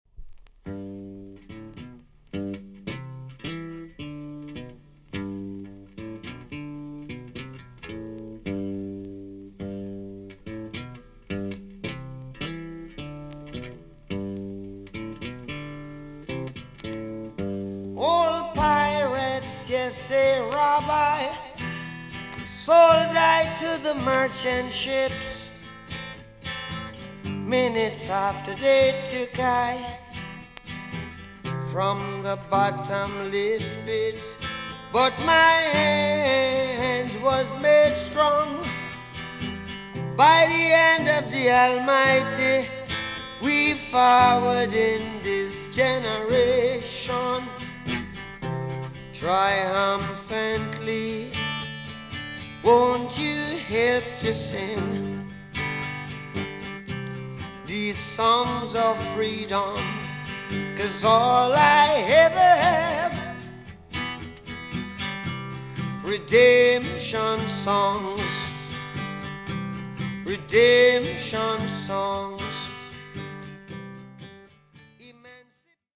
TOP >LP >VINTAGE , OLDIES , REGGAE
B.SIDE EX 音はキレイです。